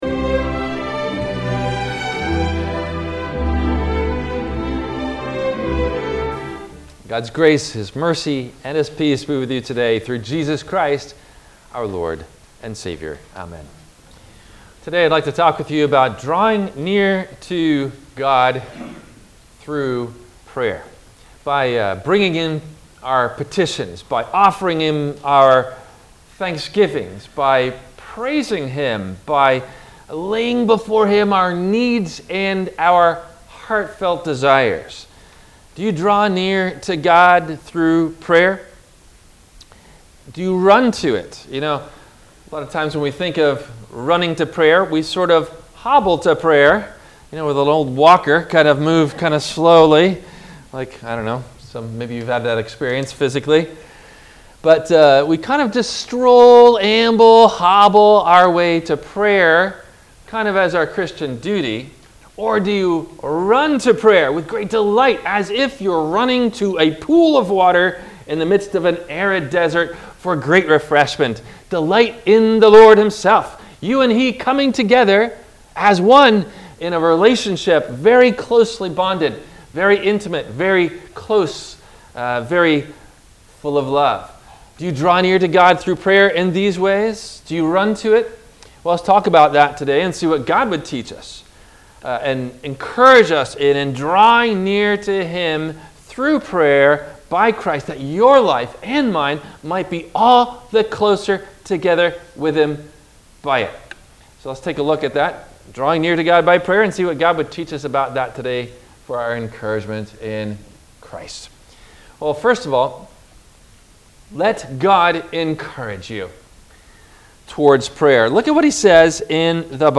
Drawing Near To God Through Prayer – WMIE Radio Sermon – April 04 2022 - Christ Lutheran Cape Canaveral